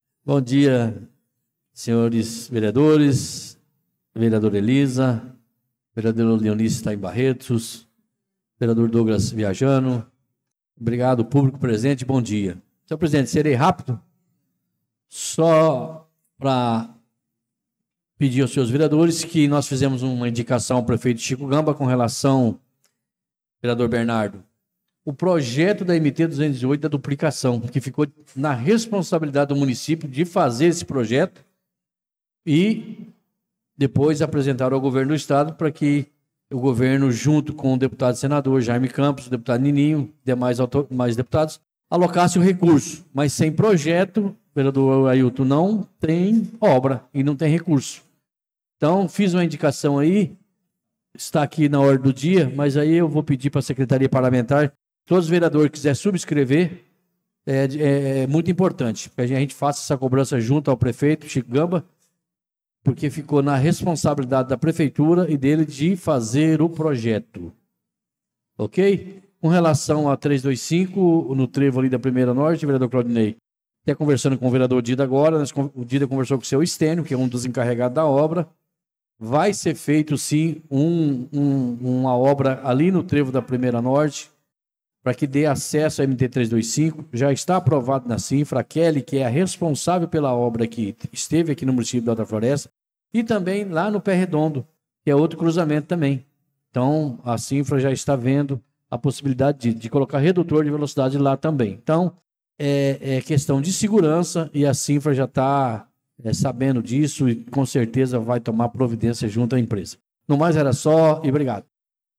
Pronunciamento do vereador Tuti na Sessão Ordinária do dia 11/02/2025